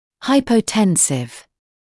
[ˌhaɪpə(u)’tensɪv][ˌхайпо(у)’тэнсив]гипотензивный, понижающий артериальное давление